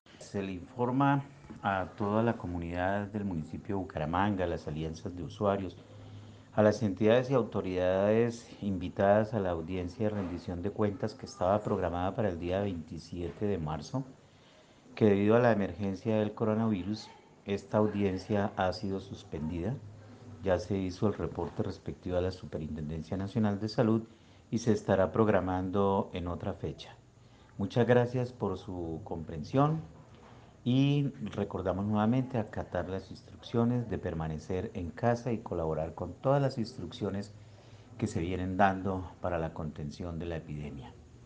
Juan Eduardo Durán Durán, gerente ESE ISABU
Juan-Eduardo-Duran-gerente-ISABU-APLAZAN-AUDIENCIA.mp3